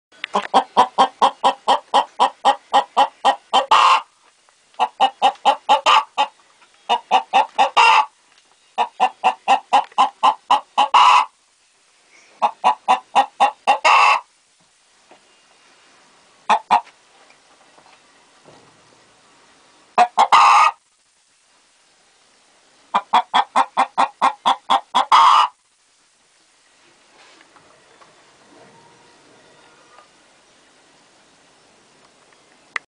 Poule-qui-chante-.mp3